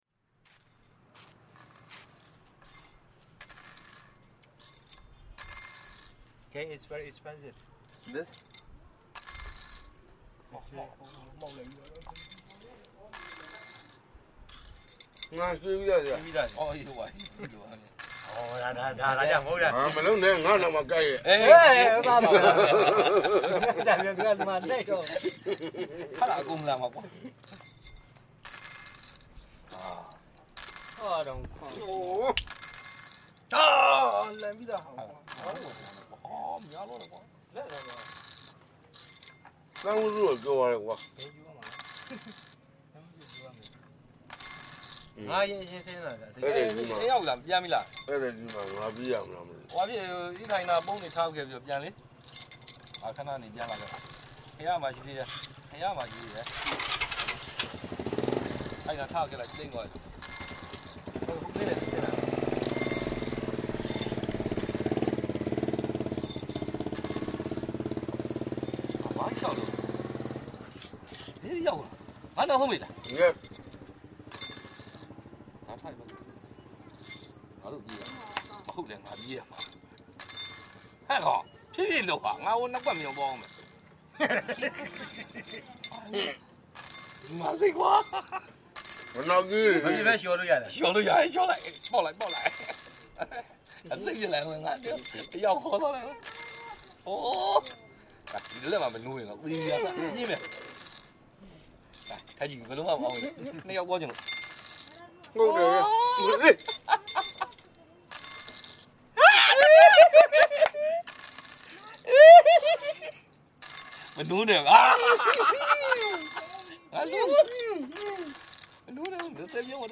A group of people play Kyway En, a rolling dice-like game using seashells, in Bagan, Myanmar.